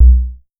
TAXI BASS 2.wav